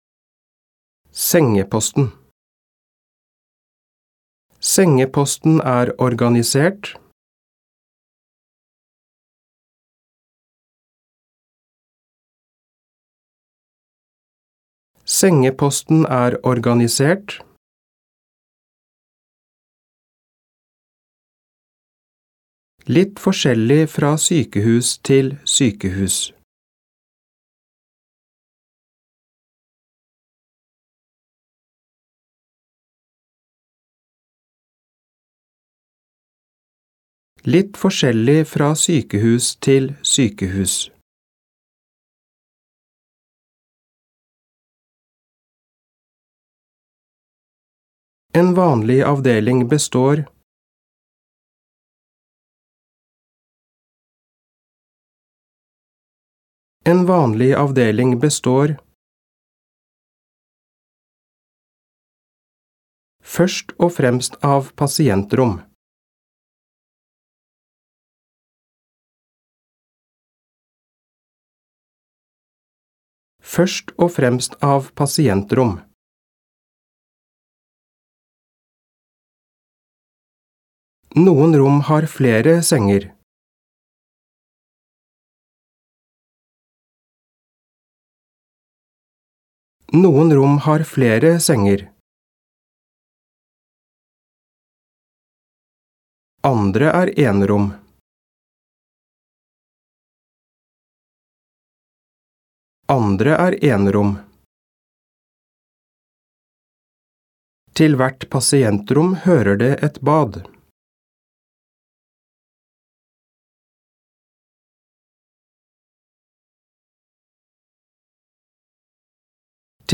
Diktat leksjon 3
• Andre gang leses hele setninger og deler av setninger.